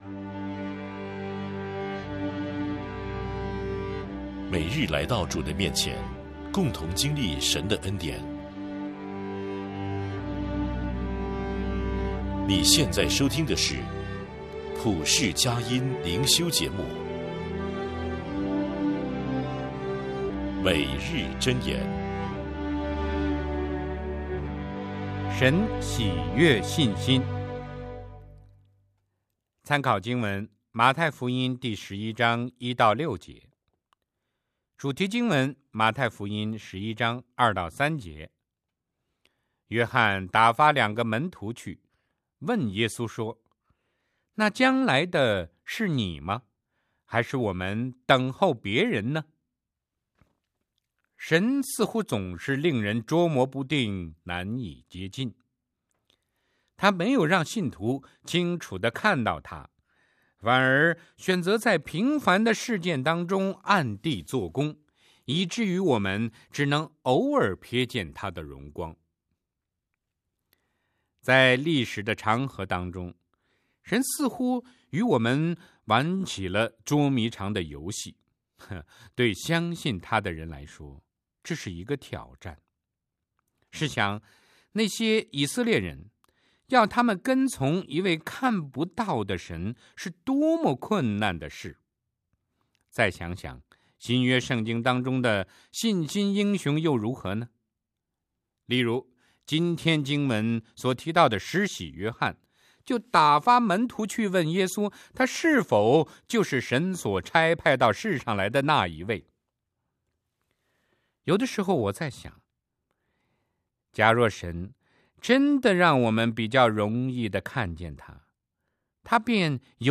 诵读:
片头: